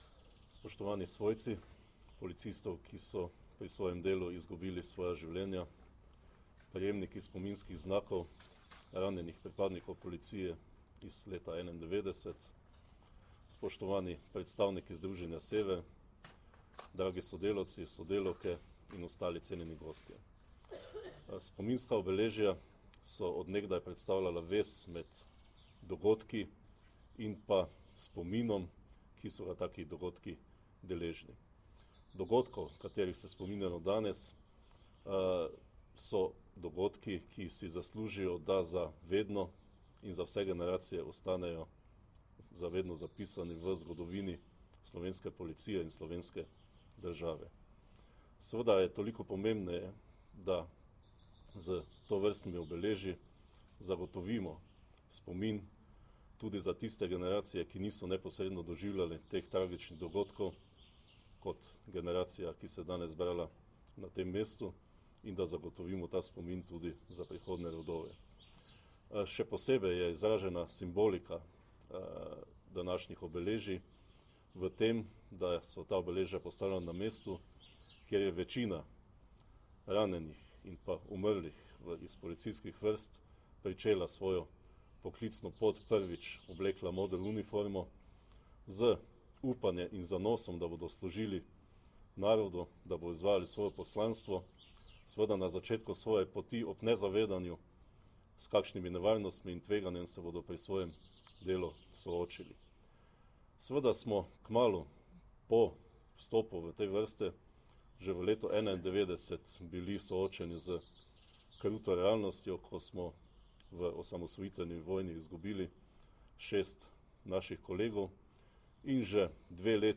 Zvočni posnetek govora generalnega direktorja policije Marjana Fanka (mp3)